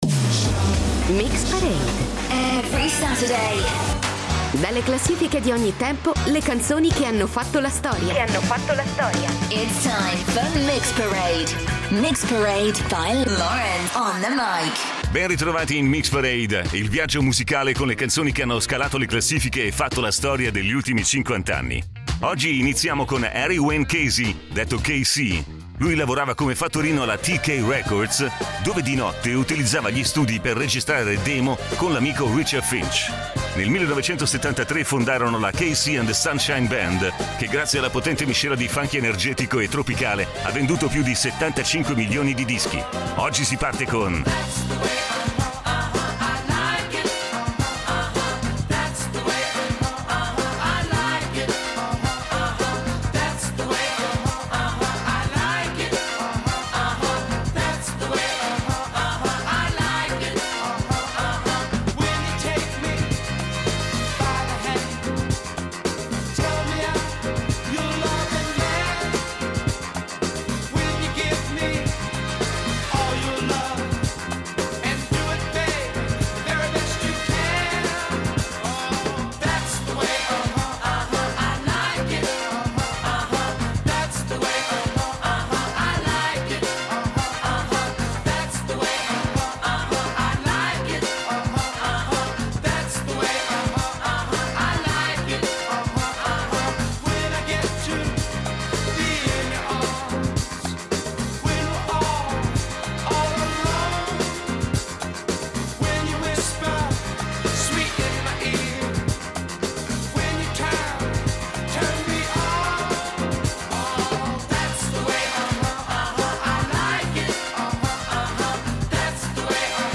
un inno della disco music anni ’70